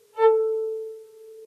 lobby_chat.ogg